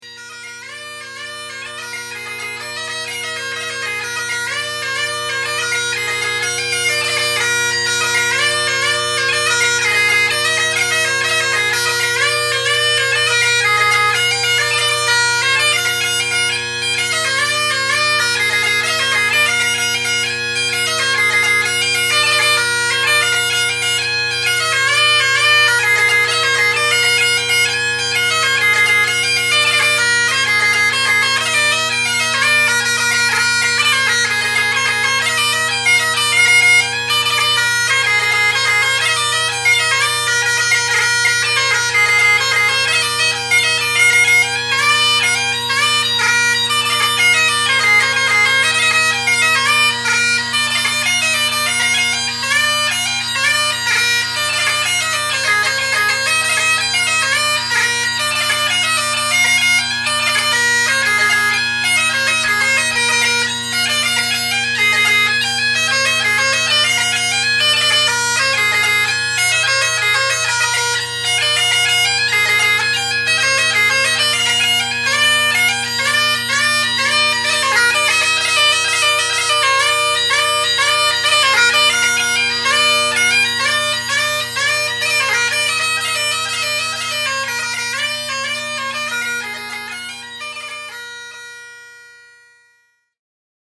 a jig